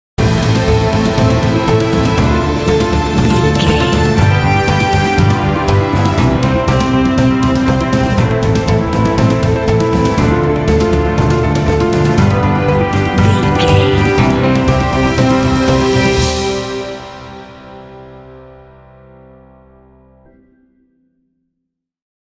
Epic / Action
Fast paced
Ionian/Major
Fast
powerful
dreamy
drums
horns
cinematic